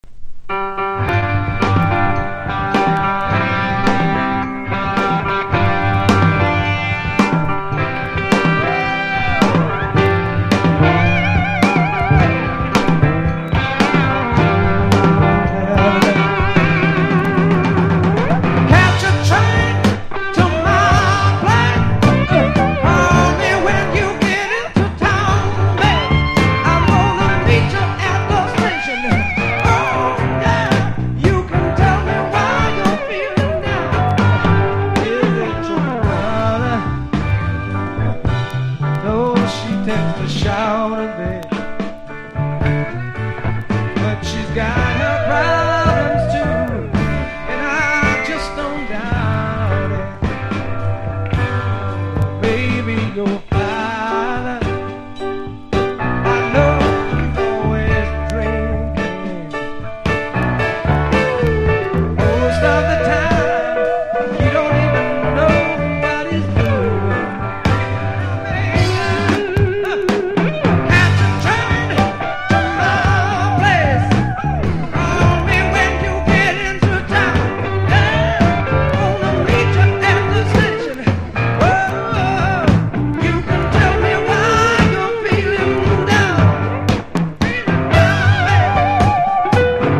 1. 70'S ROCK >
BLUES ROCK / SWAMP